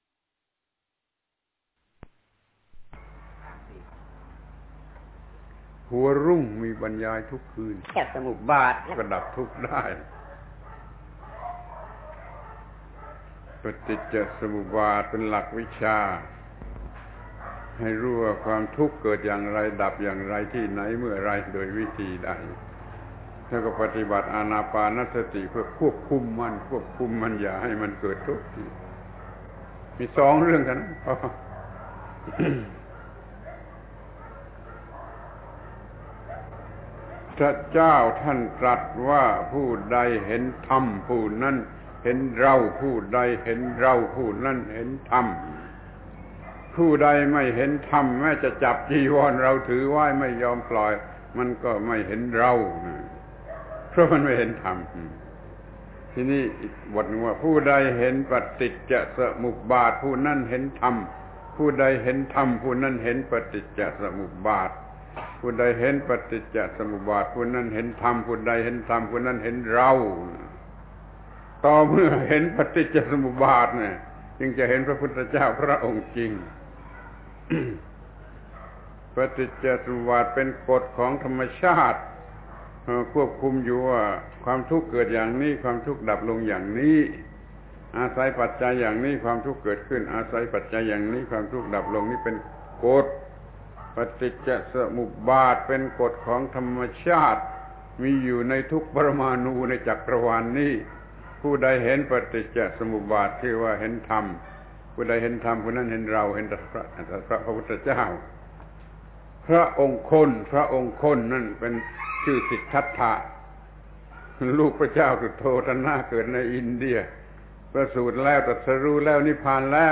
บรรยายแก่ภิกษุสามเณรจากศานติไมตรี